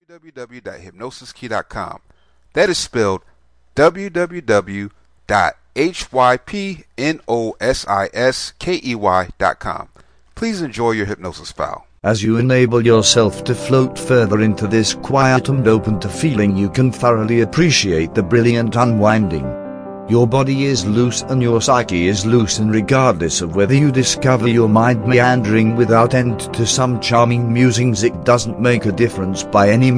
Self Expectations Hypnosis Mp3